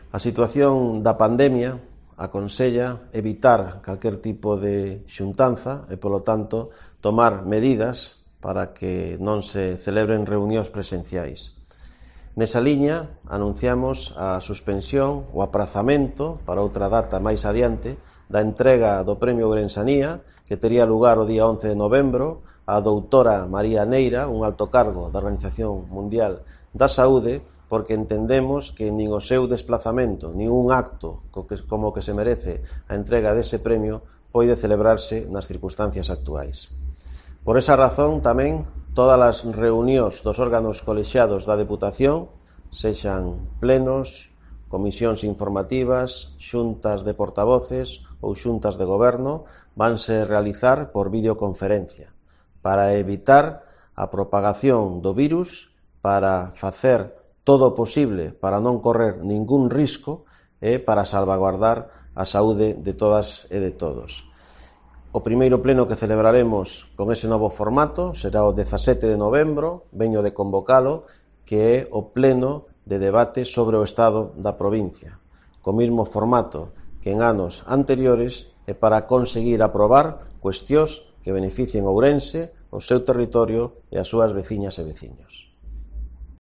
Declaraciones de Manuel Baltar sobre el acto de la Ourensanía